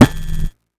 sci-fi_spark_electric_device_active_01.wav